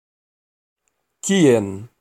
Ääntäminen
Etsitylle sanalle löytyi useampi kirjoitusasu: où ou OU Synonyymit ayoù Ääntäminen France (Paris): IPA: [u] Haettu sana löytyi näillä lähdekielillä: ranska Käännös Ääninäyte 1. kien 2. kie Suku: m .